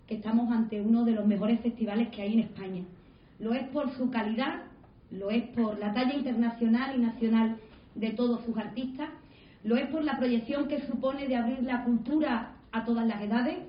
Presentación del Concert Music Festival en Sevilla